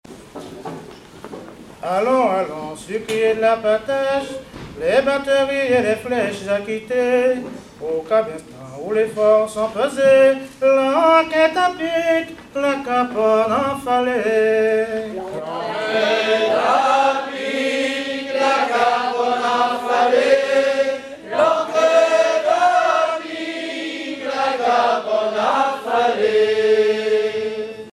Mémoires et Patrimoines vivants - RaddO est une base de données d'archives iconographiques et sonores.
chansons anciennes recueillies en Guadeloupe
Pièce musicale inédite